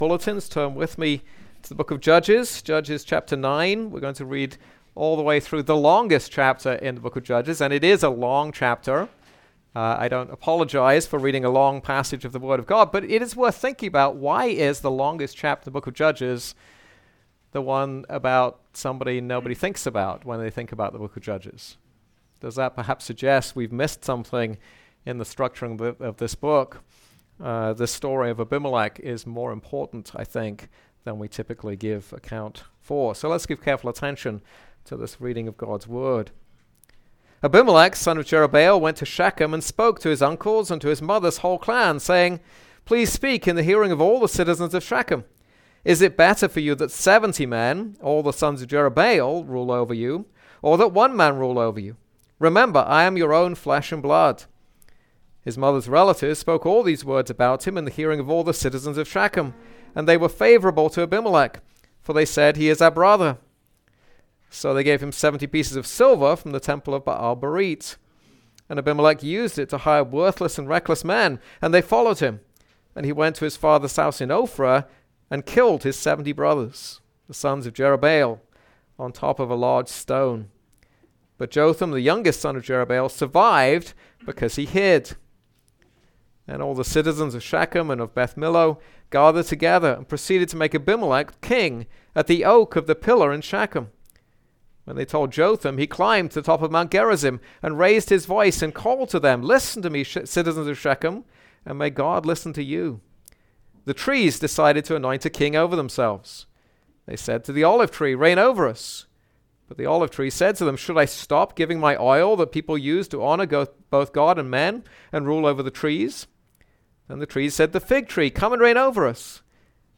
This is a sermon on Judges 9:1-10:5.